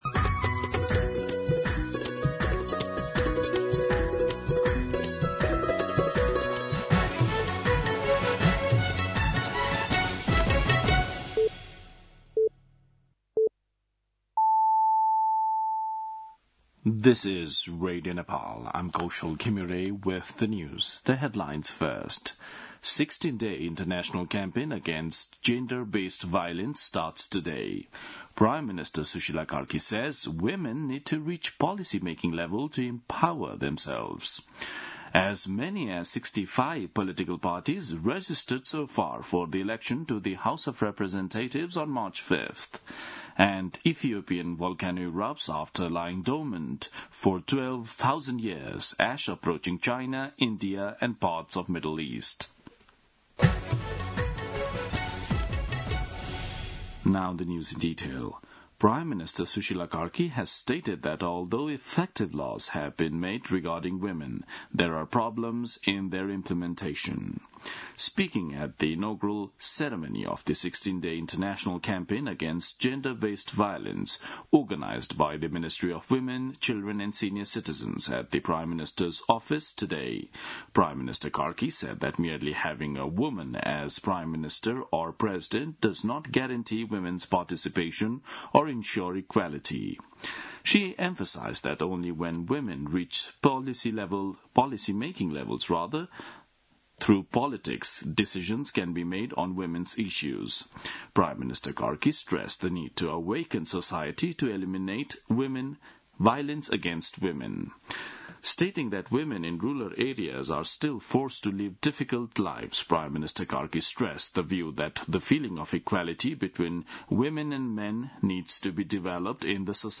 दिउँसो २ बजेको अङ्ग्रेजी समाचार : ९ मंसिर , २०८२
2-pm-English-News-8-9.mp3